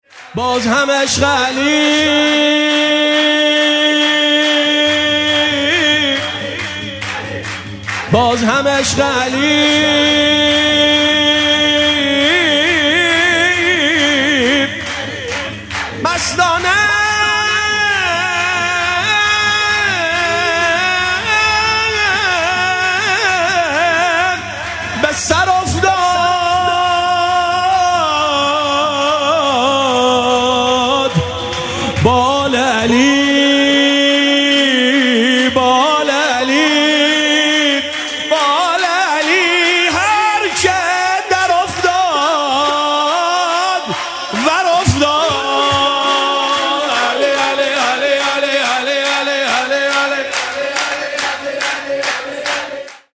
خیمه گاه - روضةالشهداء - سرود باز هم عشق علی مستانه به سر افتاد
شب میلاد حضرت معصومه (س)، ۱۰ خرداد ۴۰۱